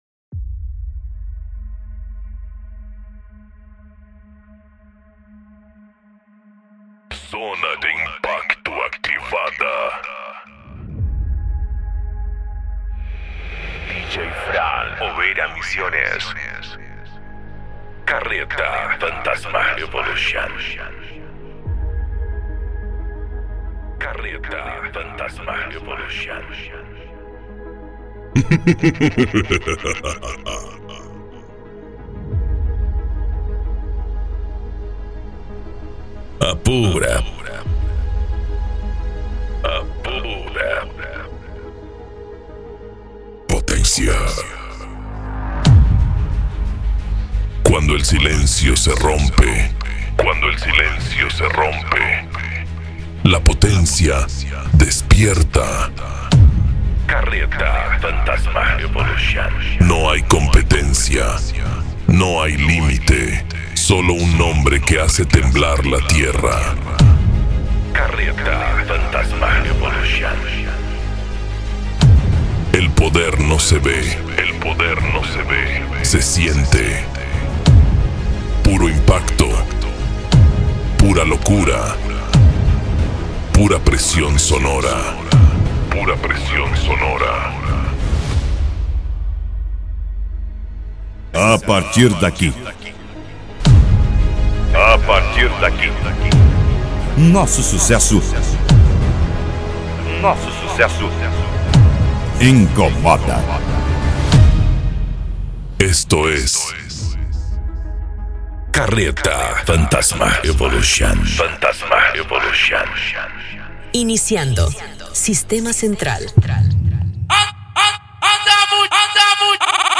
Bass
Deep House
Eletronica
Mega Funk